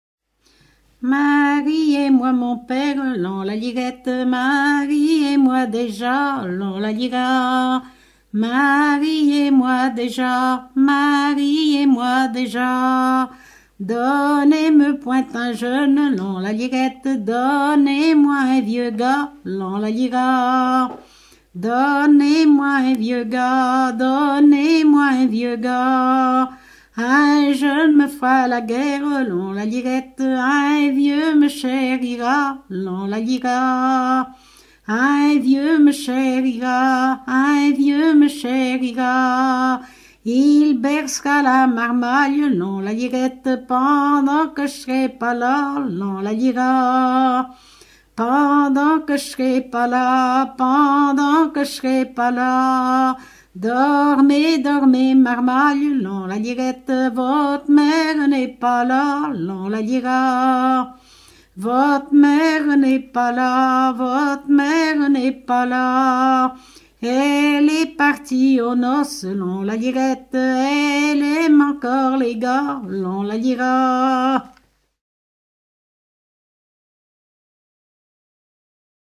Localisation Châteauneuf
Genre laisse